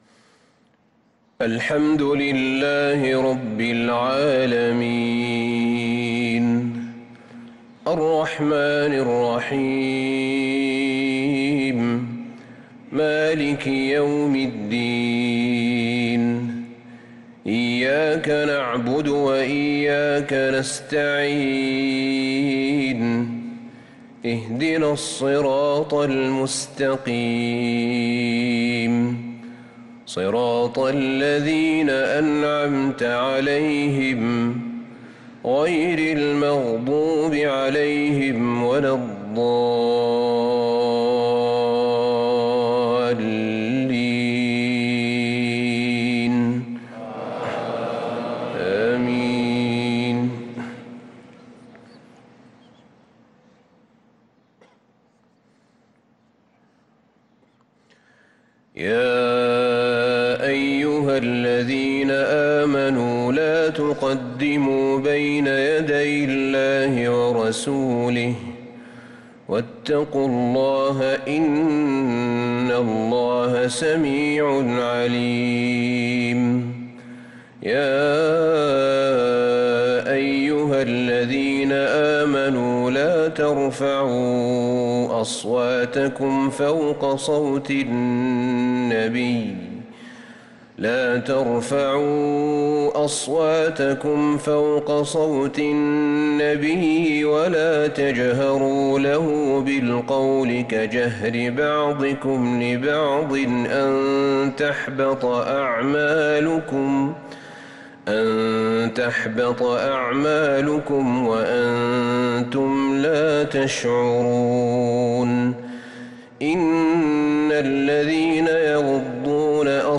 فجر الأحد 5-3-1446هـ سورة الحجرات كاملة | Fajr prayer from Surah al-hujurat 8-9-2024 > 1446 🕌 > الفروض - تلاوات الحرمين